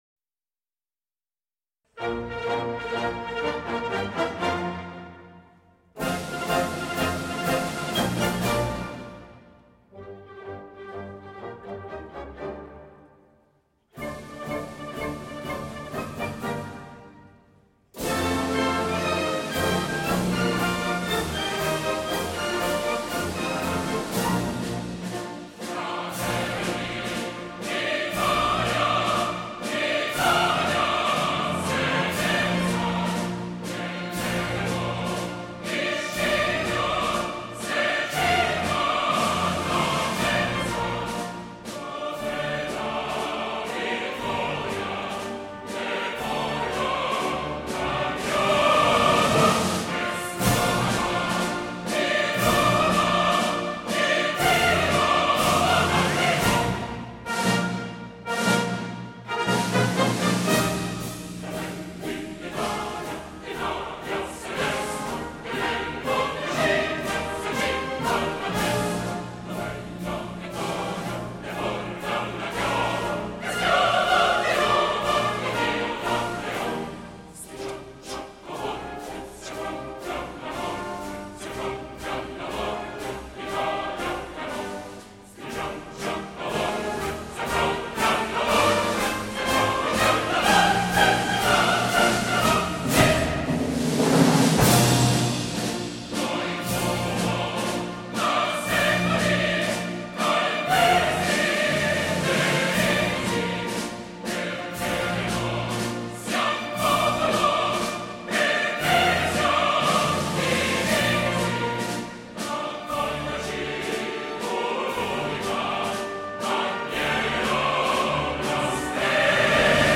INNO di MAMELI-coro-(1).mp3